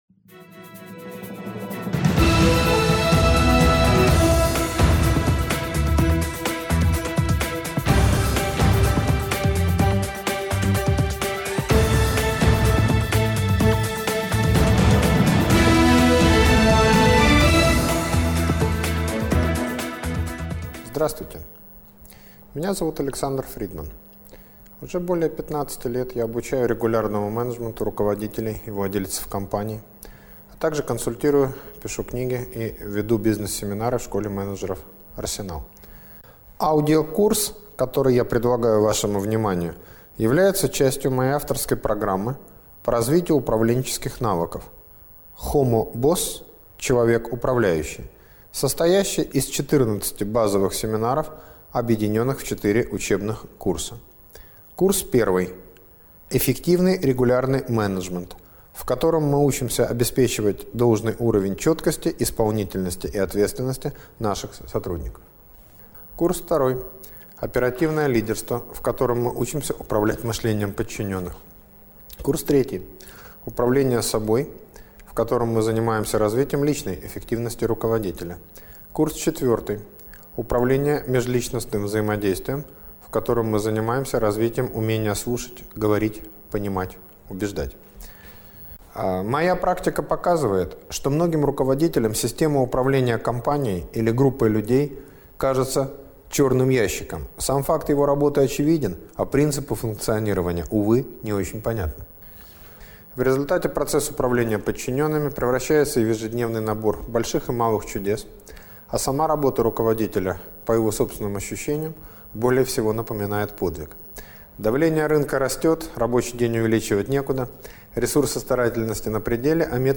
(двухдневный семинар)